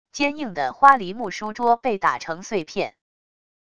坚硬的花梨木书桌被打成碎片wav音频